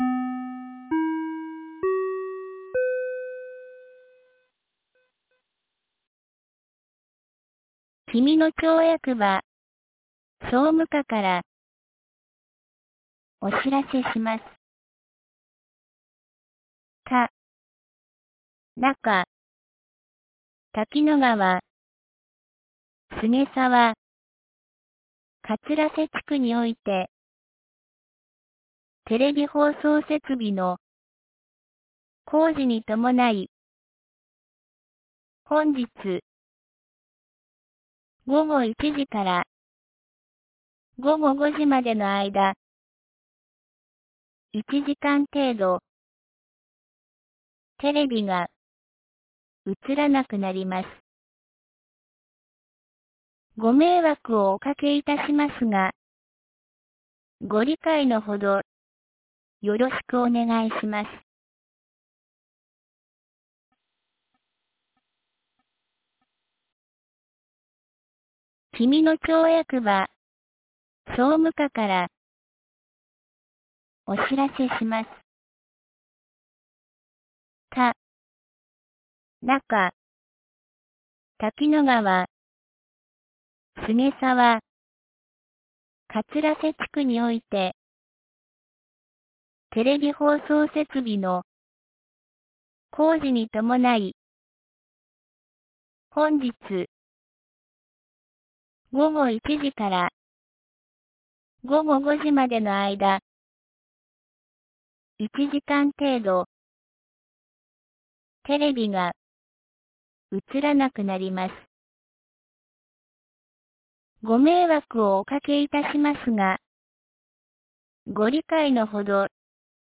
2023年12月11日 12時37分に、紀美野町より国吉地区、上神野地区へ放送がありました。